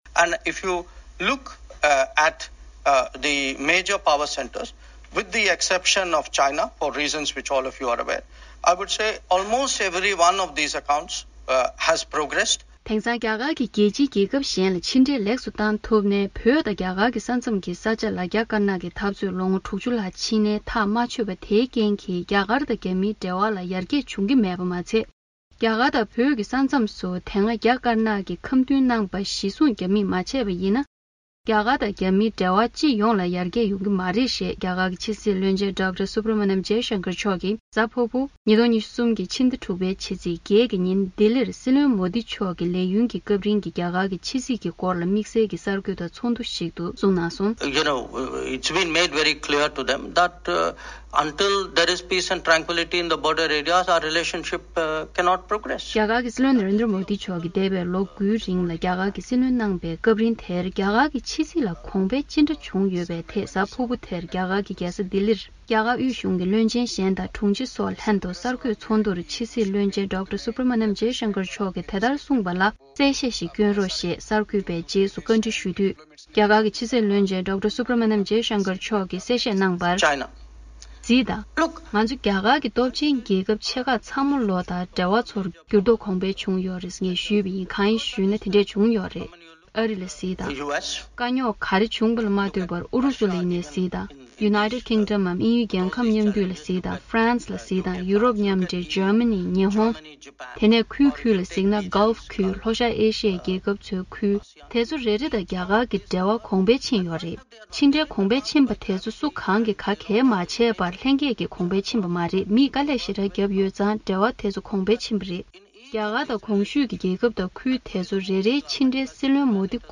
སྙན་སྒྲོན་གནང་གི་རེད།